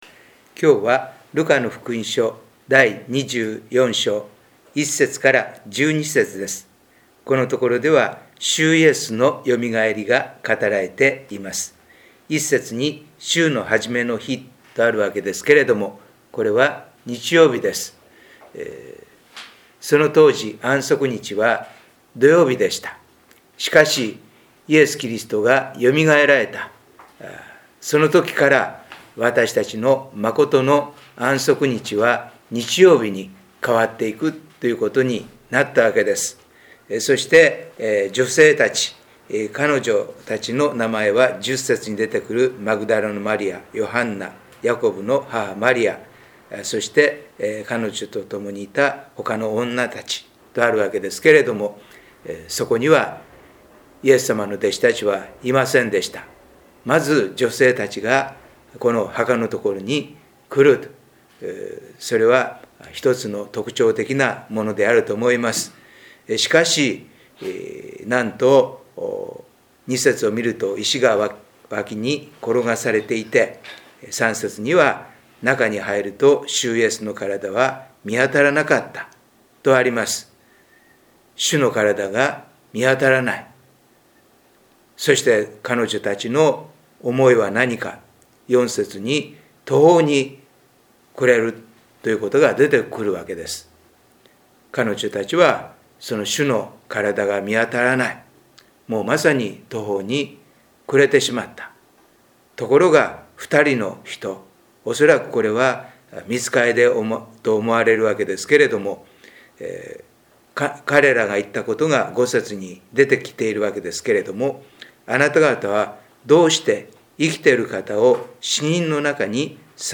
礼拝メッセージ「信仰による成長」│日本イエス・キリスト教団 柏 原 教 会